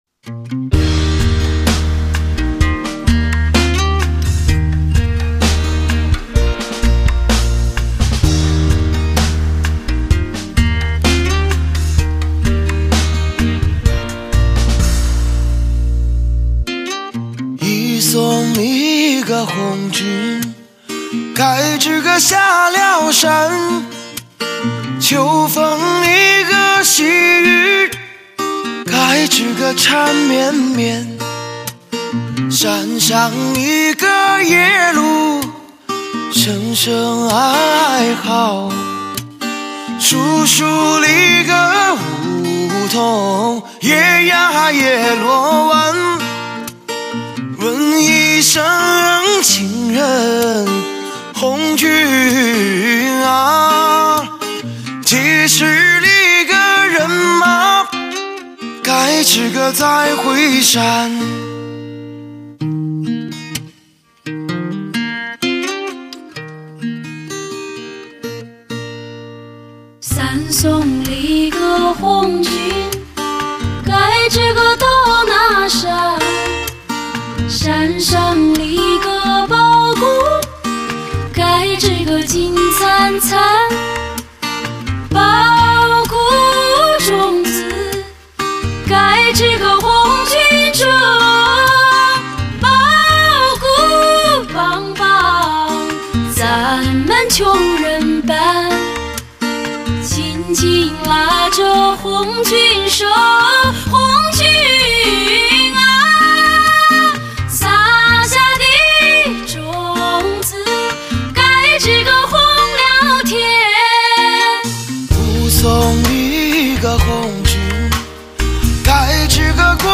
HI-FI顶级人声测试天碟
嗓音，有点狂野，有点沧桑，有点执着......